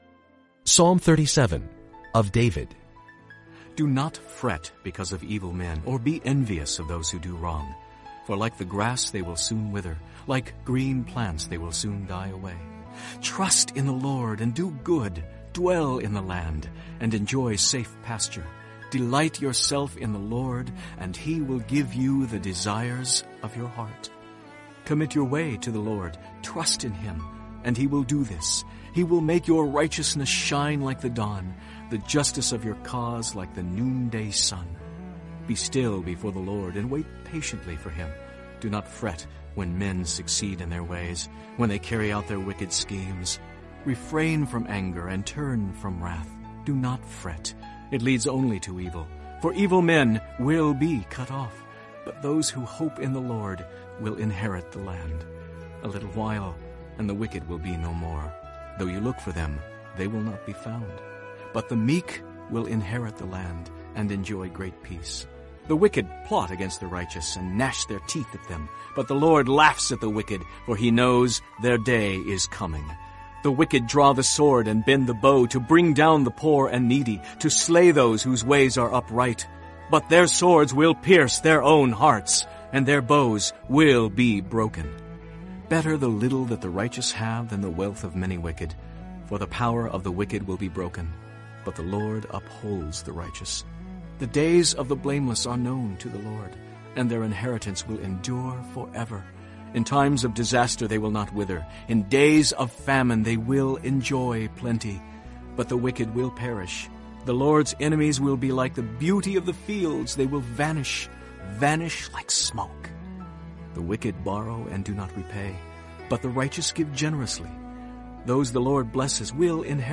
【经文朗读】（中文）